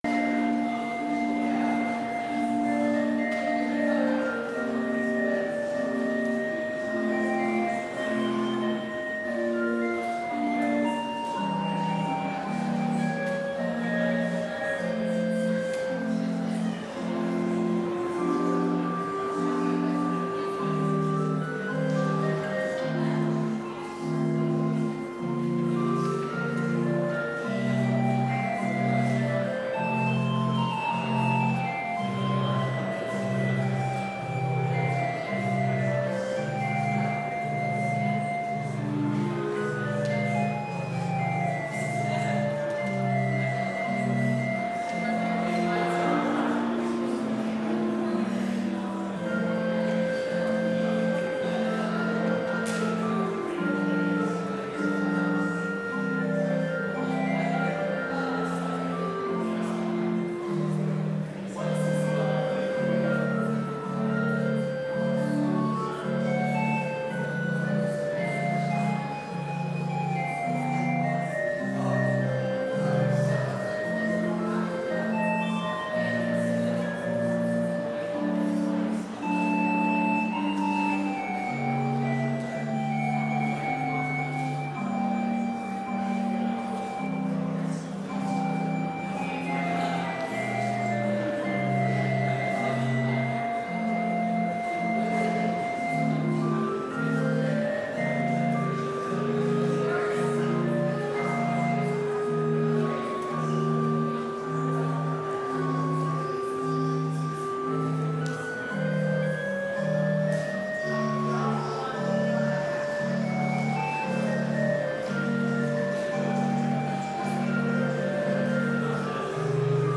Complete service audio for Chapel - February 14, 2022